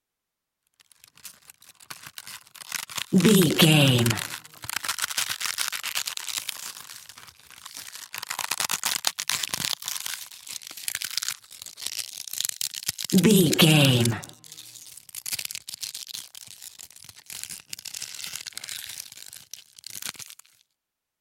Leafs fresh crush tree long
Sound Effects
nature
foley